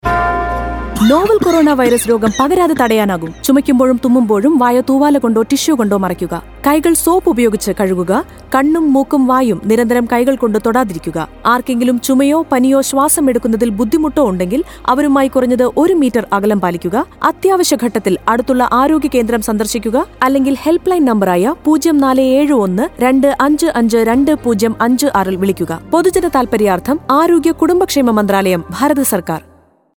Radio PSA
5152_Cough Radio_Malayalam.mp3